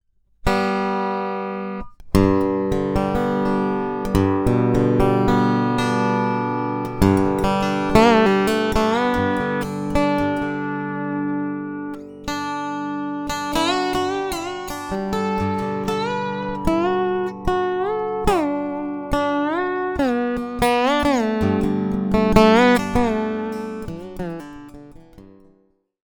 Zvuky snímačů – Custom Resophonic Guitars
(Zvuky jsou bez přidaných efektů a předzesilovačů jako např. Fishman AURA atd…)
Snímač Fishman Undersaddle (piezo-elektrický instalovaný pod kobylkovým plátkem):